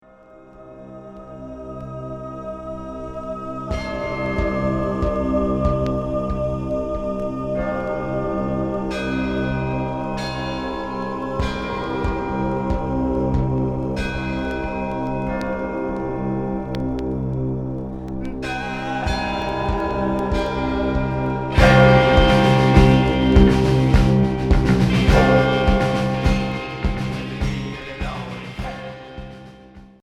Gothique expérimental